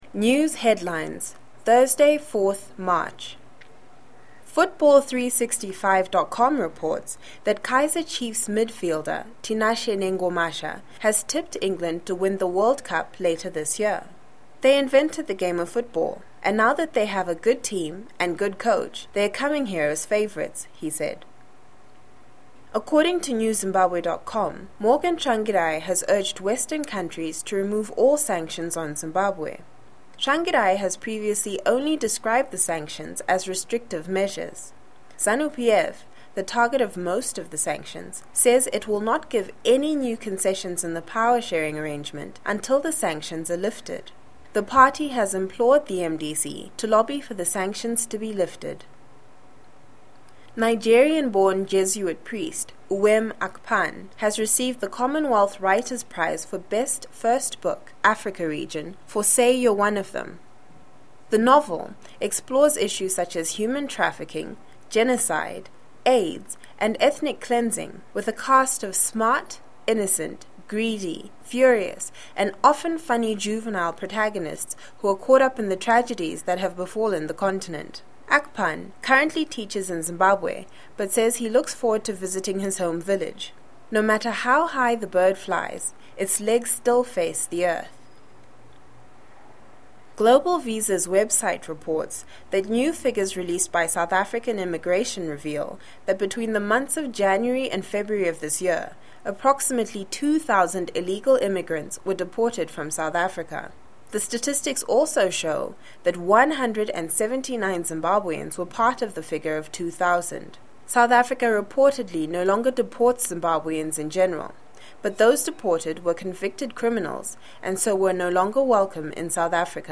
News headlines     shares a round up of local, regional and international news daily